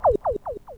LogoutNew.wav